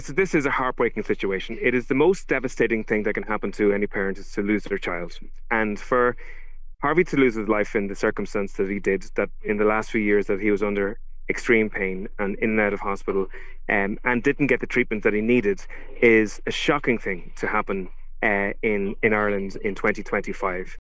Earlier, Aontu leader Peader Toibin called on Simon Harris to resign: